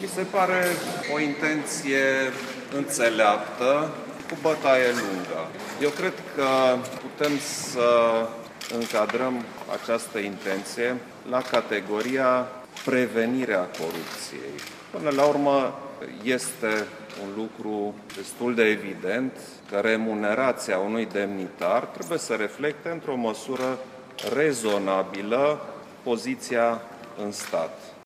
Chiar înainte de anunţul premierului interimar, preşedintele Klaus Iohannis a apreciat iniţiativa Guvernului :